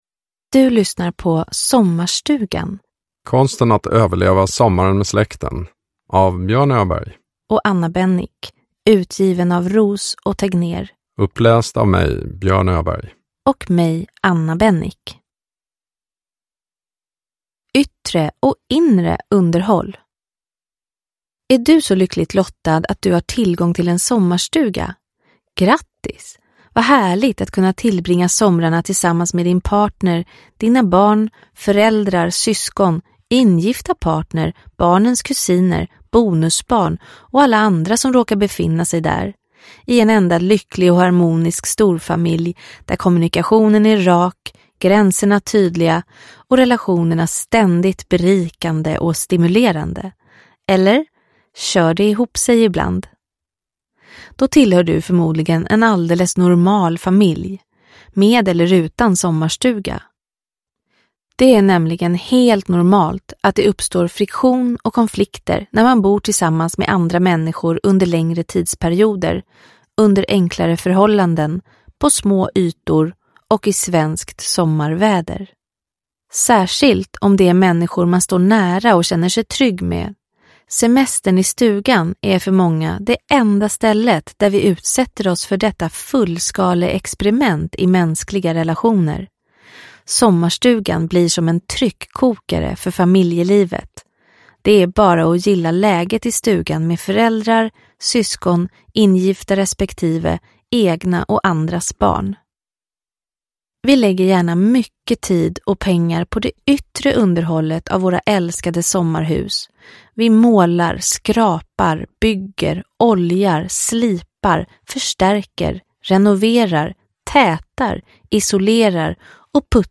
Sommarstugan – konsten att överleva sommaren med släkten – Ljudbok – Laddas ner